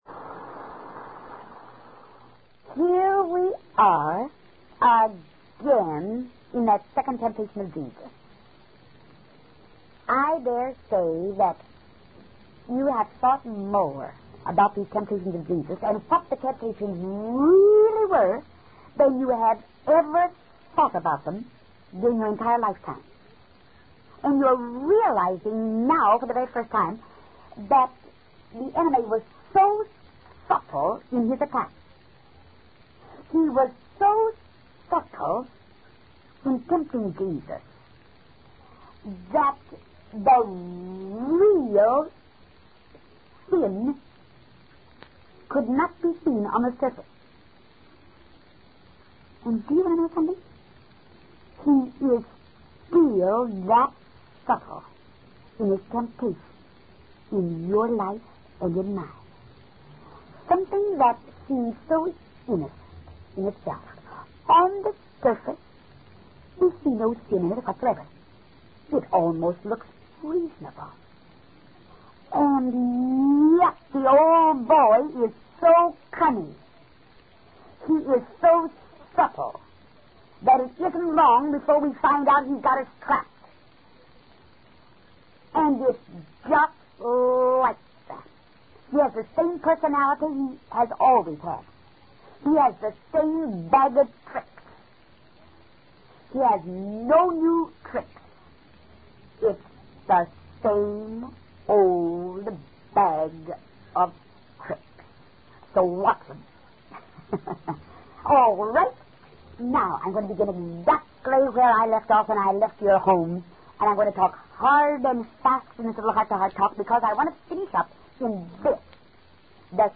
In this sermon, the preacher discusses the temptation of Jesus in the holy city. He focuses on the second of the three templates, where the devil tempts Jesus to jump off the pinnacle of the temple to prove his trust in God.